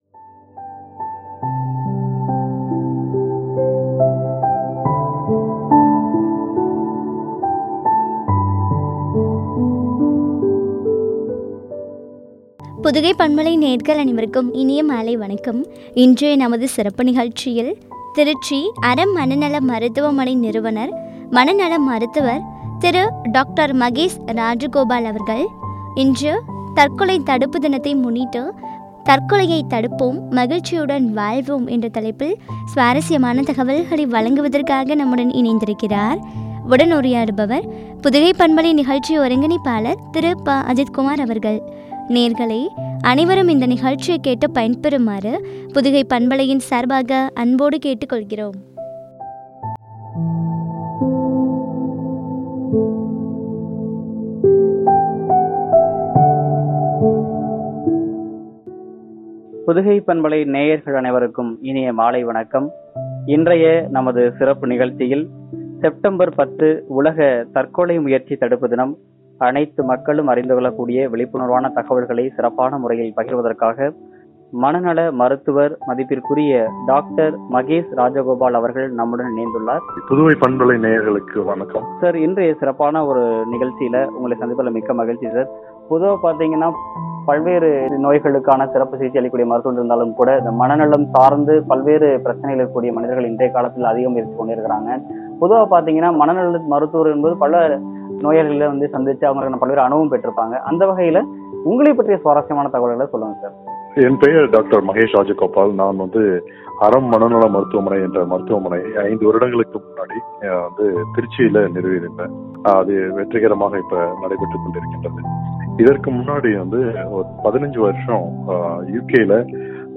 குறித்த வழங்கிய உரையாடல்.